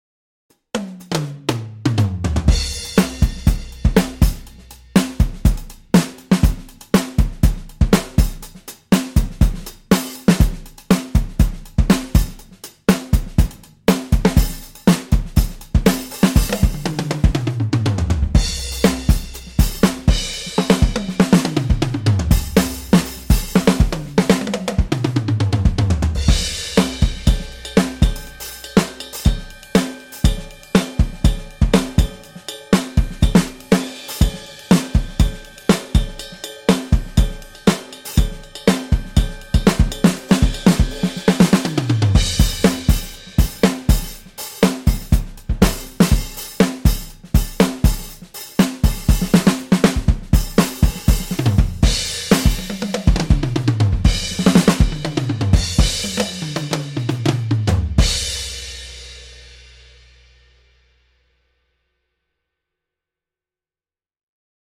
六 个原声鼓套件和超过 400 种经典鼓机声音
在英国伦敦的英国格罗夫工作室拍摄
为了增加更加有机和正确的时期风味，所有鼓和机器在数字转换之前都被记录到磁带上。
热门制作人 SDX -音乐会套件